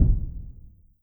EXPLOSION_Subtle_Foof_stereo.wav